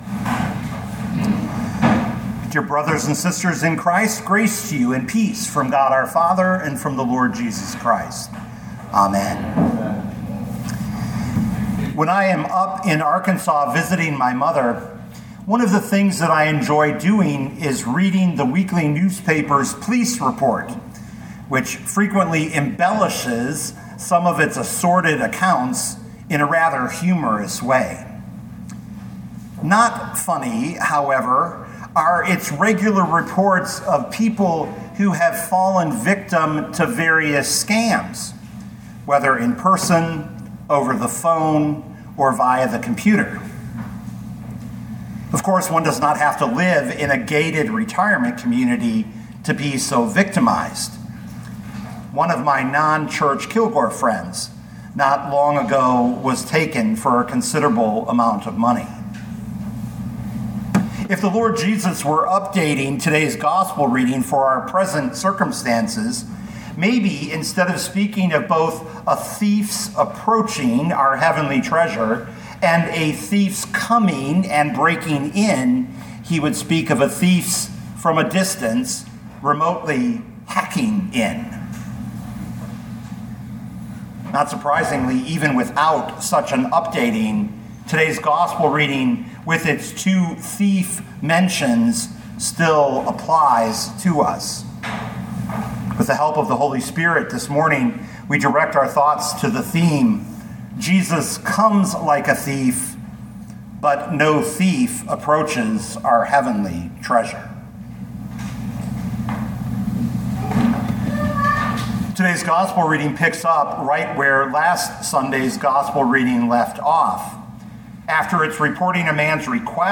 2025 Luke 12:22-40 Listen to the sermon with the player below, or, download the audio.